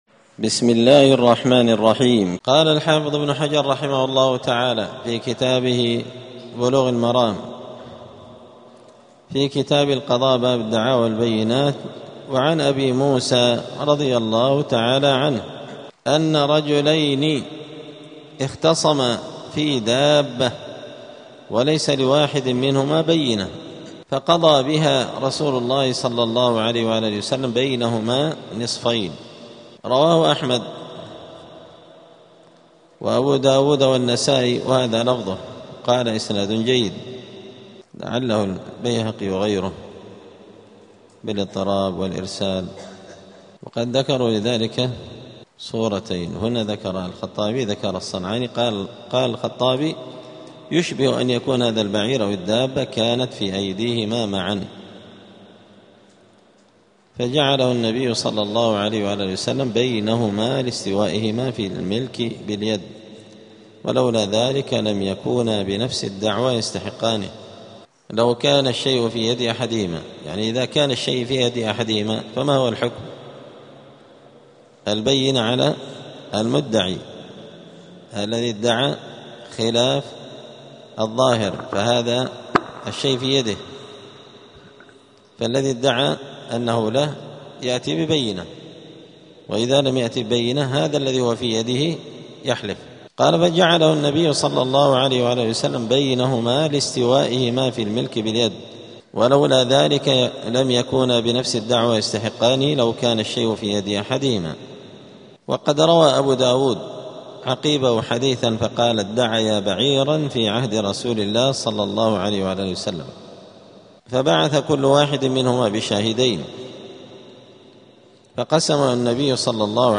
*الدرس الرابع والعشرون (24) {ﺣﻜﻢ ﻣﻦ ﺃﺧﺬ ﻣﺎﻝ ﻏﻴﺮﻩ ﺑﻐﻴﺮ ﺣﻖ}*